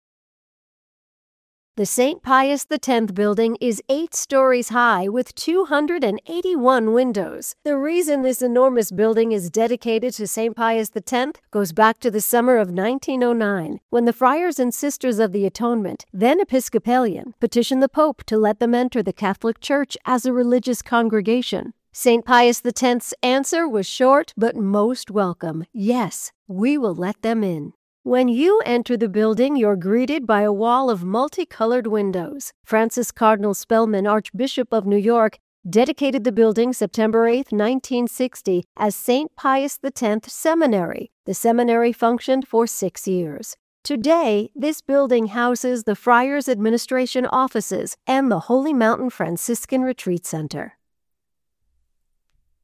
Pius-X-Building-Tour-2-1.mp3